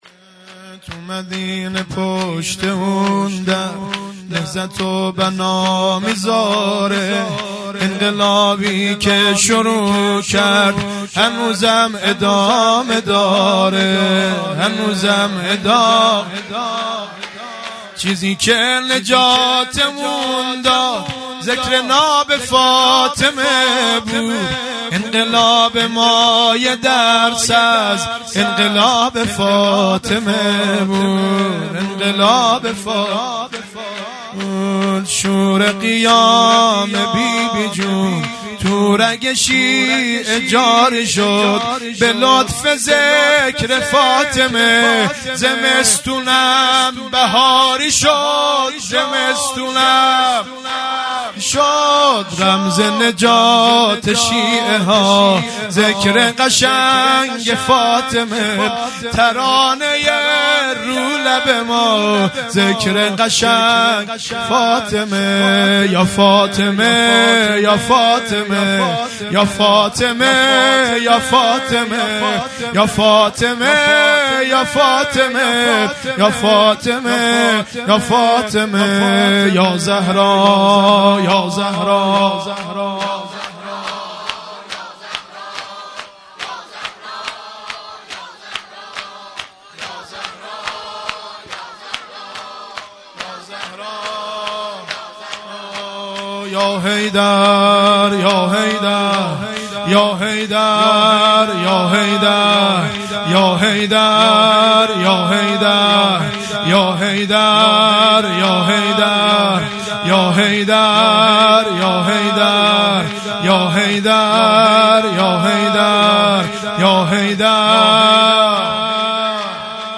0 0 شور ۲ | تو مدینه پشت اون در مداح
فاطمیه دوم ۱۴۰۱_شب چهارم